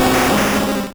Cri de Dracaufeu dans Pokémon Rouge et Bleu.